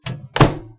微波炉门关闭1
描述：我正在制作爆米花，所以我决定在做的时候听一些声音。